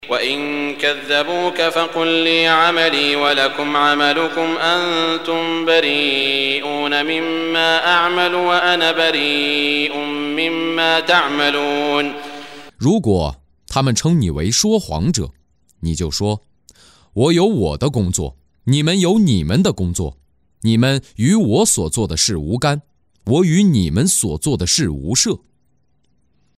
中文语音诵读的《古兰经》第（优努斯）章经文译解（按节分段），并附有诵经家沙特·舒拉伊姆的诵读